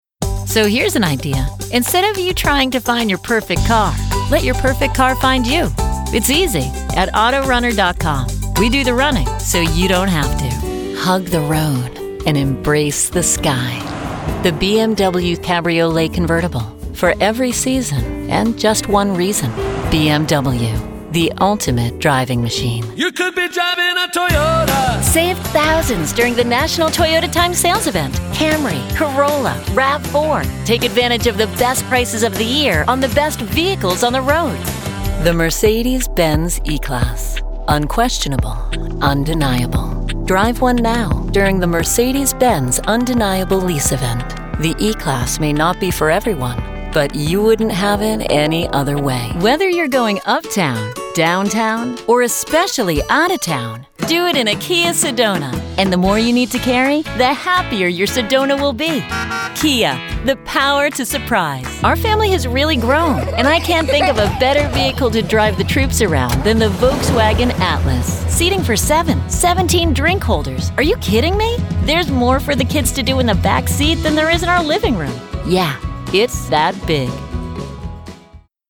Muestras de voz nativa
Automoción
Cabina vocal que se encuentra en una sala insonorizada (para mayor tranquilidad :) )